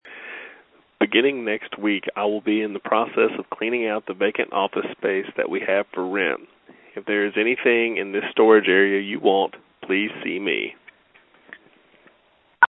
First of all, before I get into today’s  writing assignment, here’s something cool I did with WordPress today over the phone…Blogging by phone?